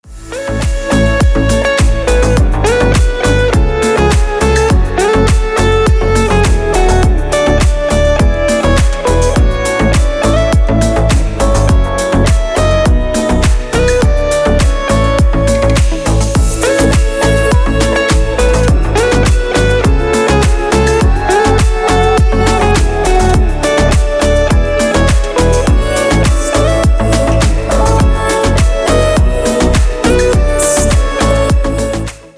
• Качество: 320, Stereo
deep house
без слов
красивая мелодия
Cover
теплые